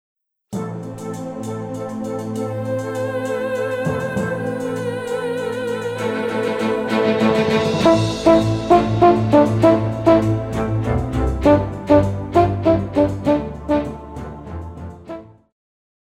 Pop
French Horn
Band
Instrumental
World Music,Electronic Music
Only backing